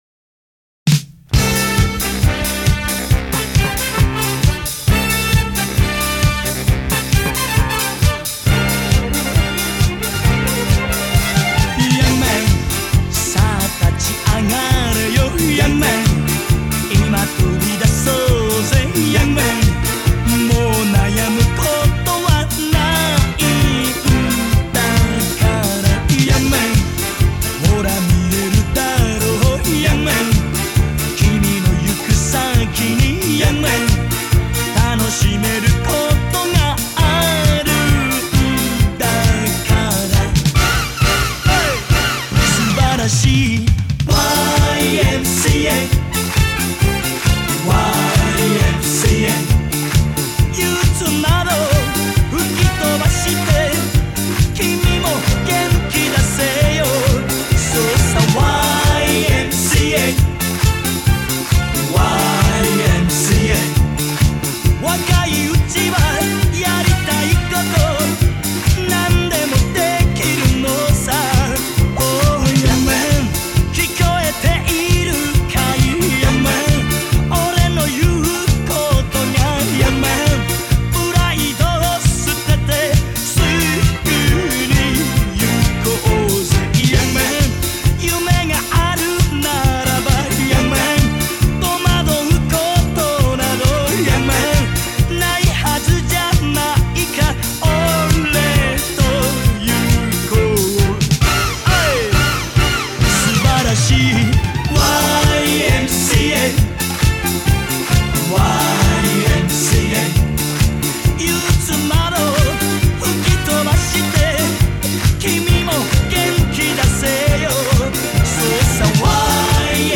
此曲当年可是轰动一时的迪斯科舞曲